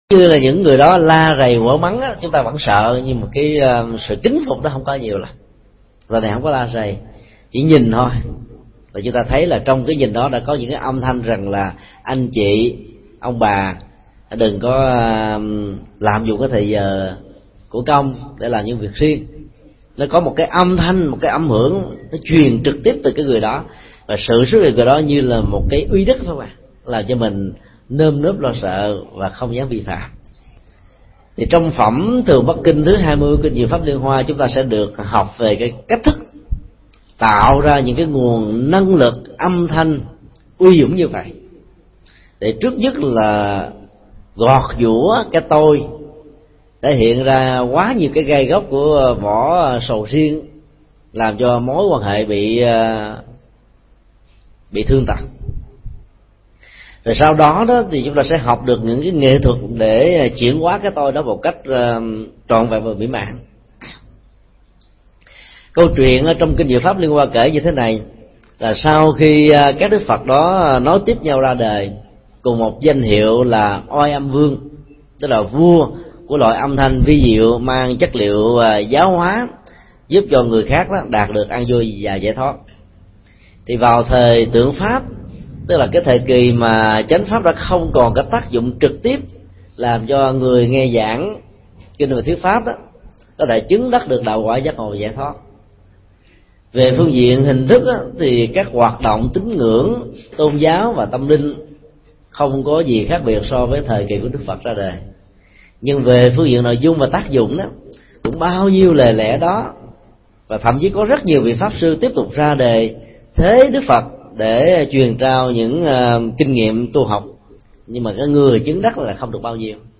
Mp3 Thuyết pháp Hạnh khiêm cung qua hình ảnh Thường Bất Khinh – thầy Thích Nhật Từ giảng tại Chùa Liên Hoa, Q.6, ngày 16 tháng 12 năm 2006.